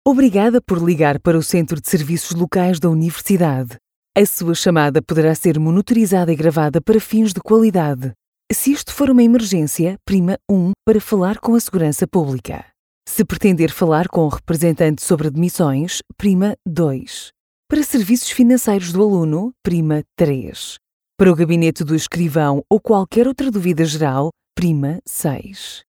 Portugal-Based Professional Voice Talent for European Portuguese
Why Choose In-Country, Portugal-Based Professional Voice Talent for European Portuguese IVR and Auto Attendant?